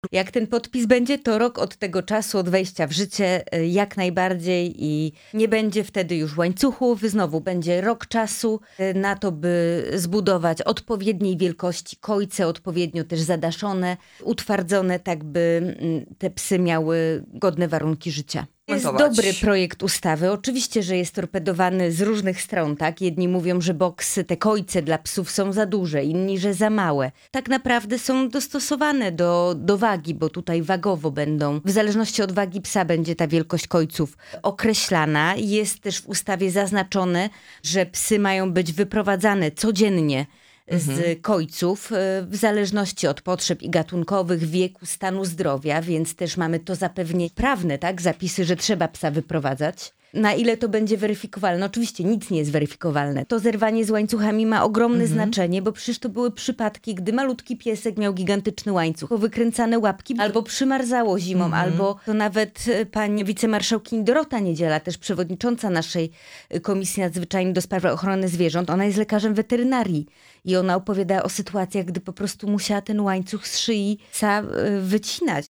Posłanka na Sejm Małgorzata Tracz byłą naszym „Porannym Gościem”.